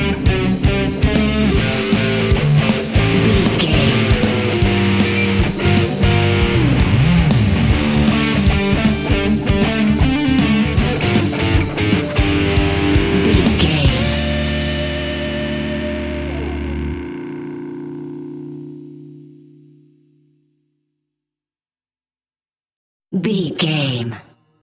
Aeolian/Minor
hard rock
blues rock
distortion
instrumentals
rock guitars
Rock Bass
heavy drums
distorted guitars
hammond organ